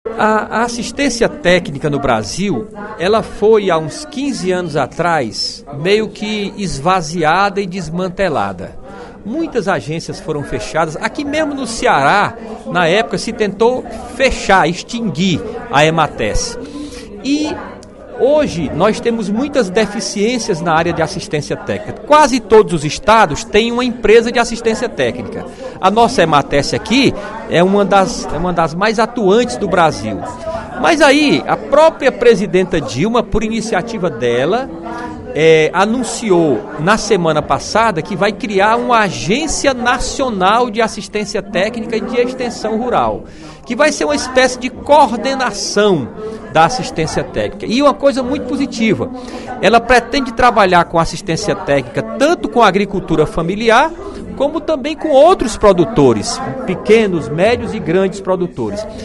O deputado Nelson Martins (PT) destacou, durante pronunciamento nesta terça-feira (03/07) na Assembleia Legislativa, o anúncio feito na última semana pela presidente Dilma Rousseff de criar uma agência nacional para cuidar da área de assistência técnica extensão rural do País.